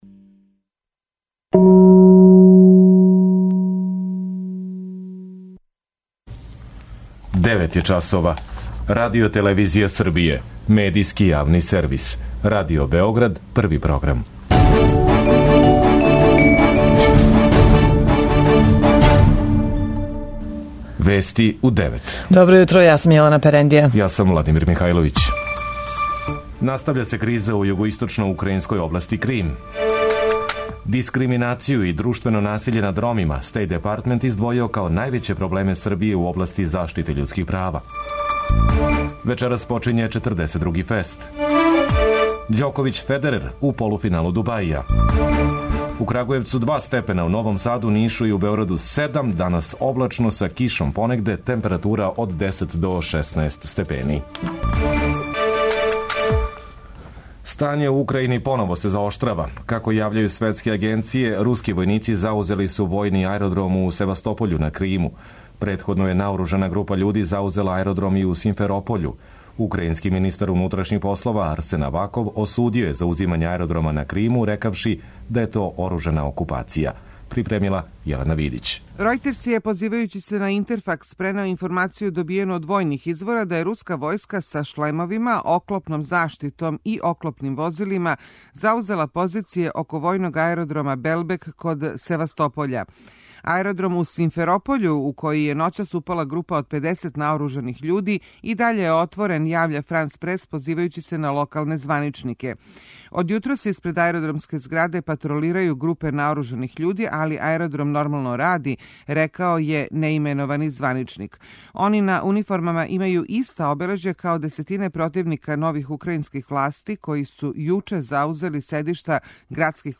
преузми : 9.37 MB Вести у 9 Autor: разни аутори Преглед најважнијиx информација из земље из света.